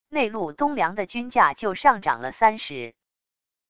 内陆冬粮的均价就上涨了30wav音频生成系统WAV Audio Player